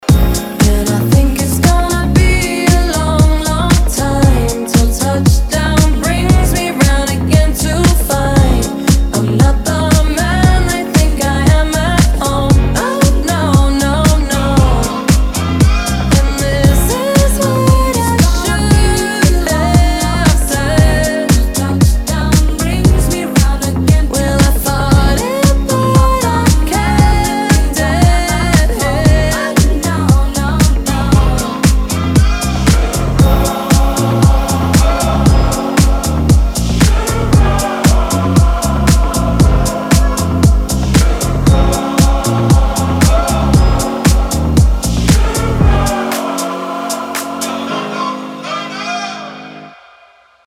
• Качество: 320, Stereo
громкие
remix
диско
дуэт